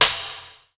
A#3 STICK0JL.wav